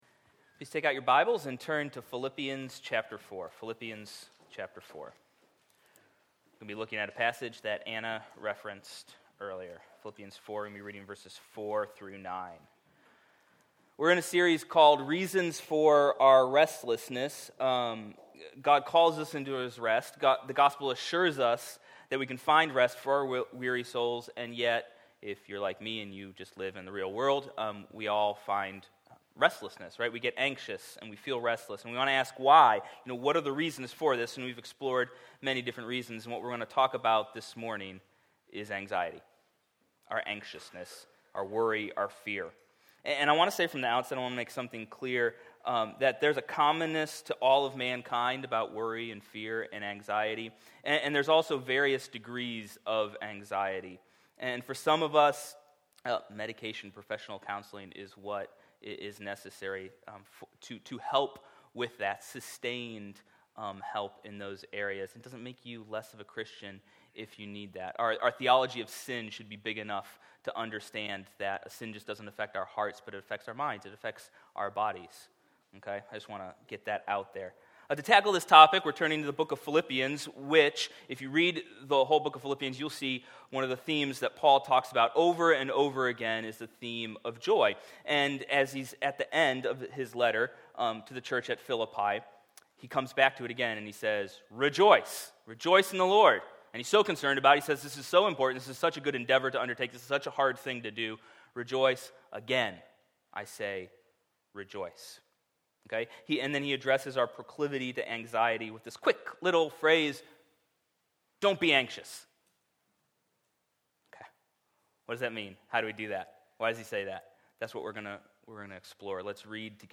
Service Type: Weekly Sunday